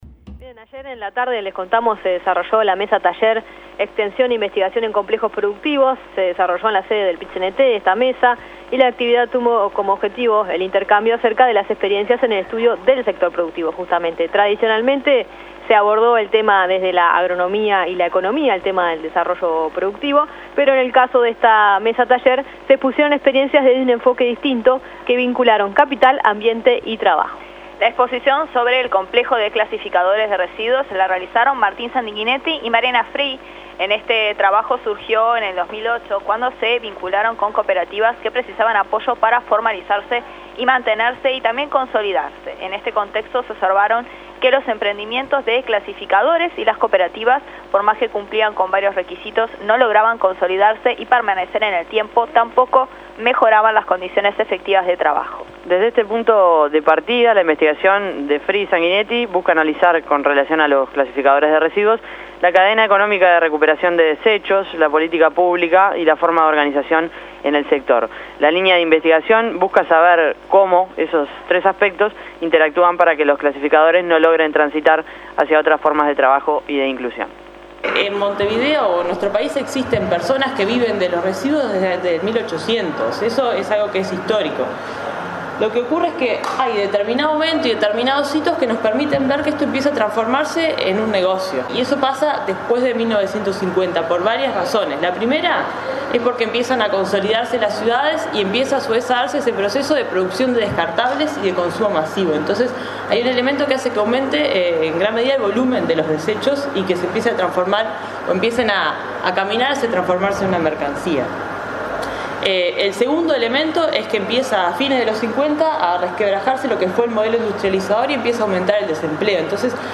En el marco del Extenso 2013, La Nueva Mañana realizó la cobertura de la mesa-taller Extensión e investigación en complejos productivos, que se realizó el miércoles 6 en la sede del PIT CNT. Actividad que tuvo como objetivo poner en común experiencias de trabajo de equipos del servicio de extensión, en el vínculo con organizaciones de trabajadores o de cooperativistas vinculados a distintos complejos productivos en el Uruguay.